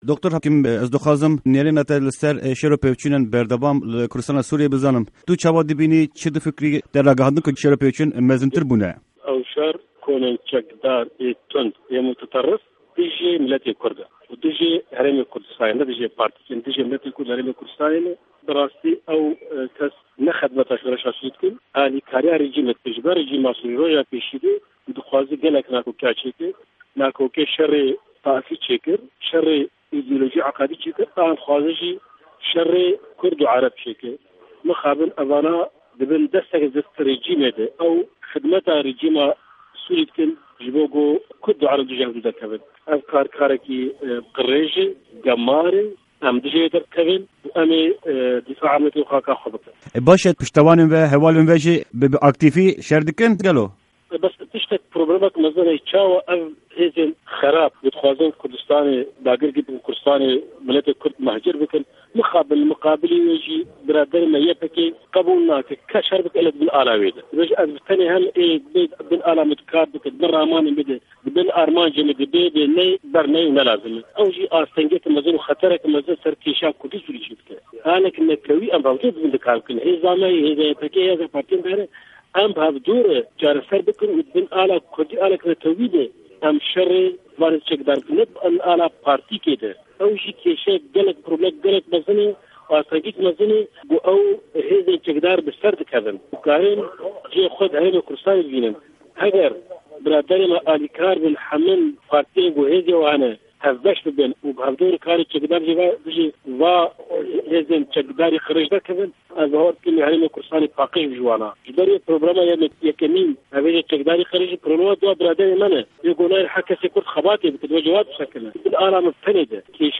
Di hevpeyvîna Pişka Kurdî ya Dengê Amerîka de Serokê Partîya Demokrata Kurdî li Sûrîyê (El Partî) Dr. Abdulhekîm Beşar ew êrîşên komên çekdar yên dijî Kurdan dinirxîne.